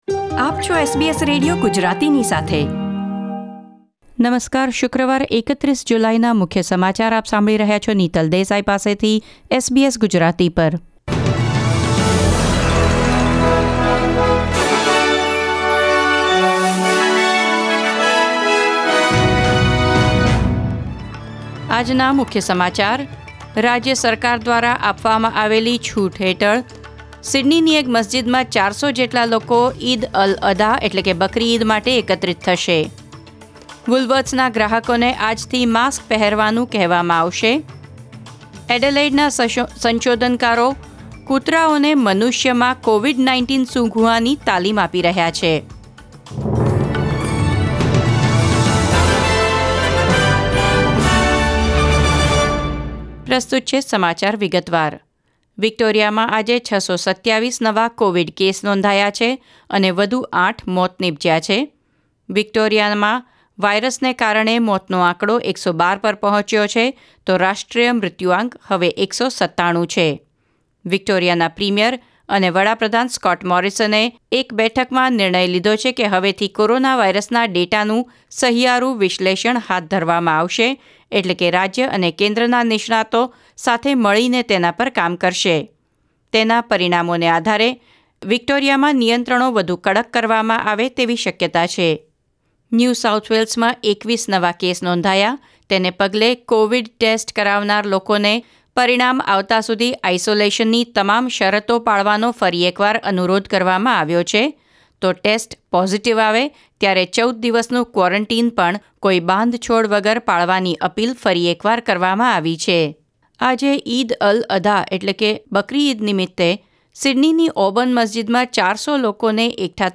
SBS Gujarati News Bulletin 31 July 2020